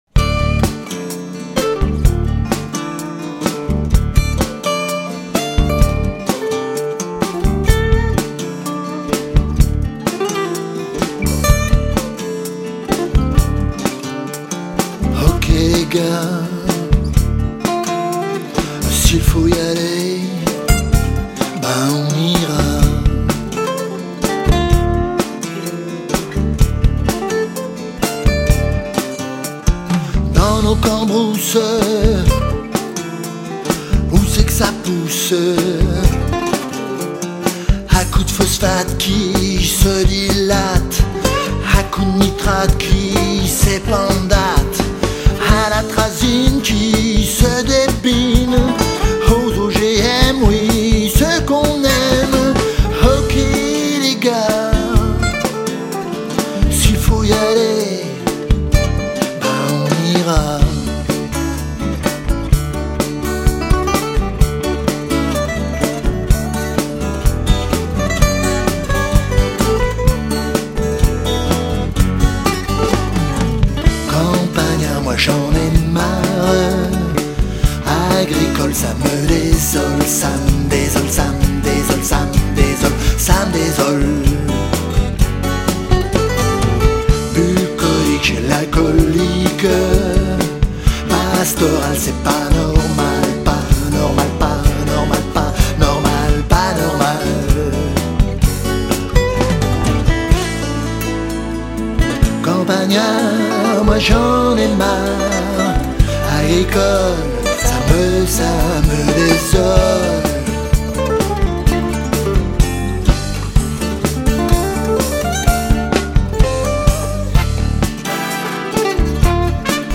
chante 13 titres originaux